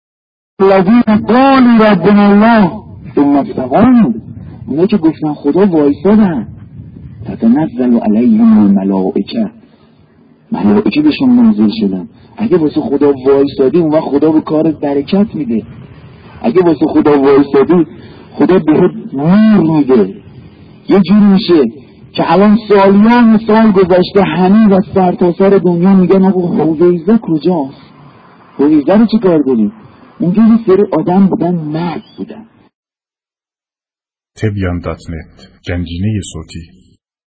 دسته بندی صوت‌ها بی سیم بیانات بزرگان پادکست روایتگری سرود شرح عملیات صوت شهدا کتاب گویا مداحی موسیقی موسیقی فیلم وصیت نامه شهدا گلف چند رسانه‌ای صوت روایتگری هویزه کجاست؟